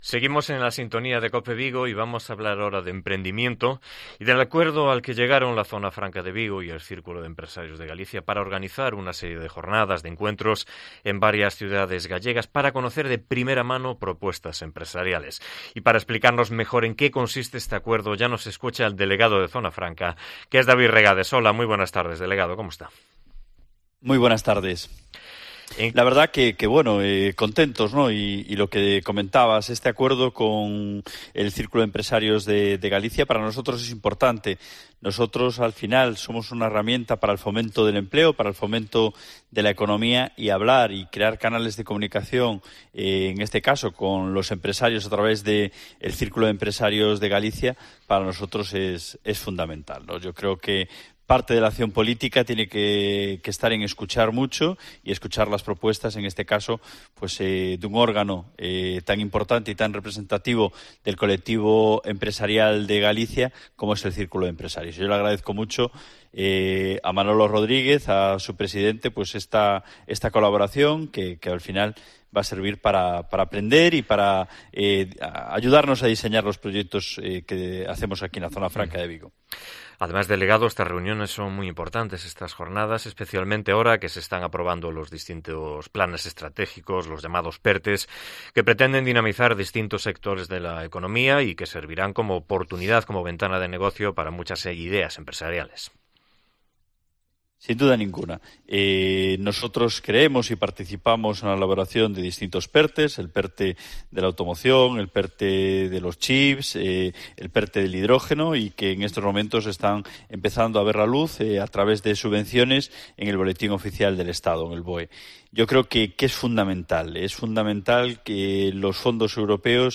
Entrevistamos al delegado de Zona Franca con el que hablamos del acuerdo con el Círculo de Empresarios de Galicia para organizar jornadas de promoción de ideas empresariales